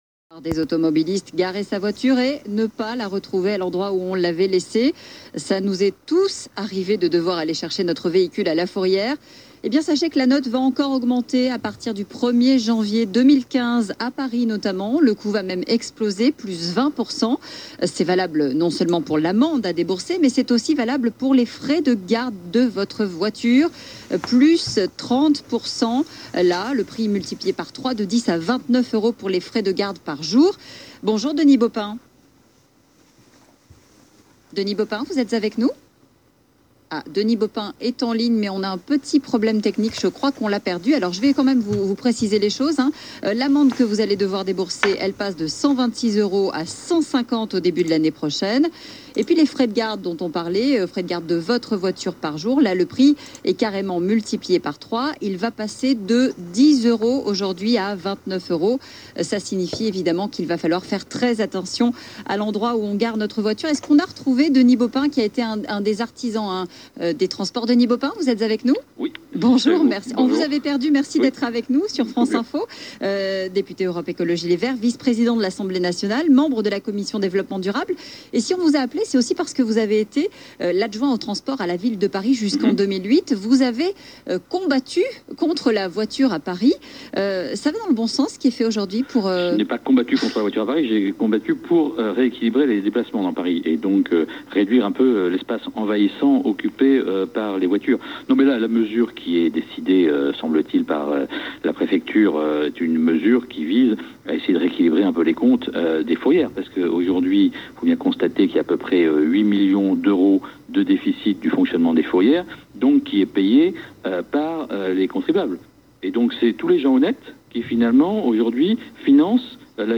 Denis Baupin était l’invité de France Info jeudi 4 décembre pour évoquer l’augmentation des tarifs des fourrières parisiennes.